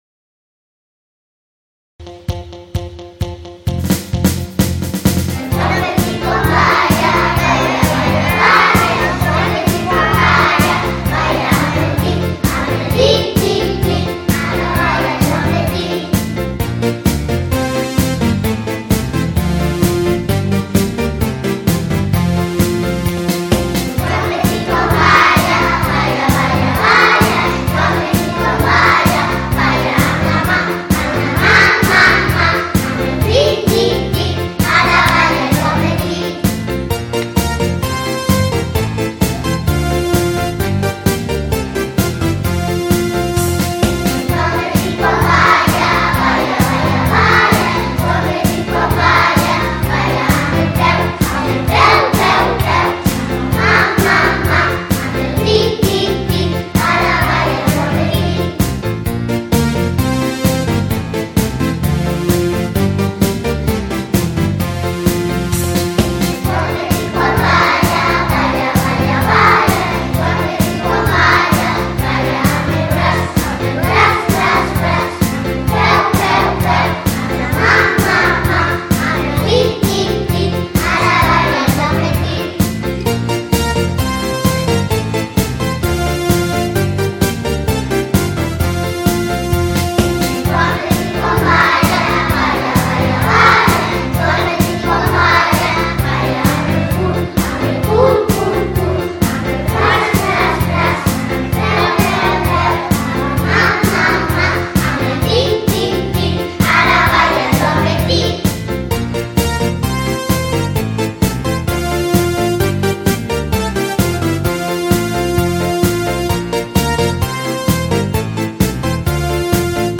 Us deixo penjat al bloc un nou episodi del CD de Cançons Populars que hem enregistrat a l’escola.
Els alumnes de P5 canten “En Joan Petit” i riuren molt cada vegada que toca dir la paraula C….